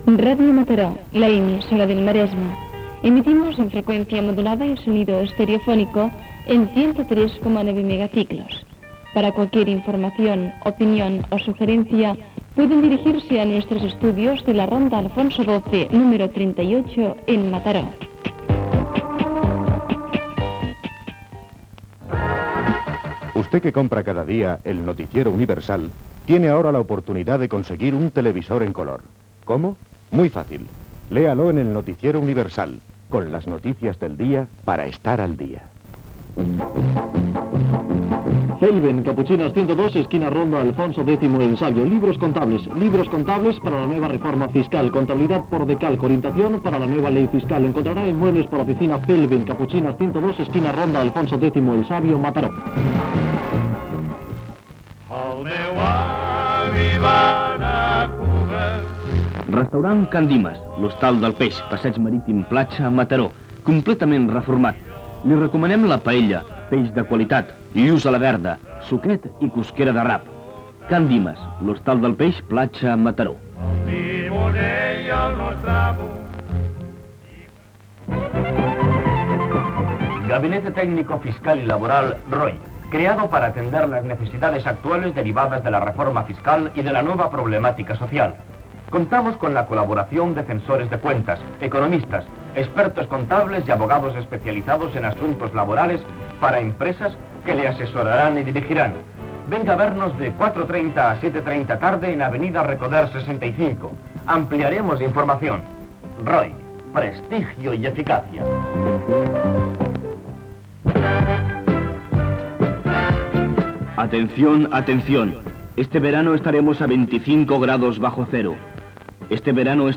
Identificació, adreça de l'emissora, publicitat, inici del programa.
FM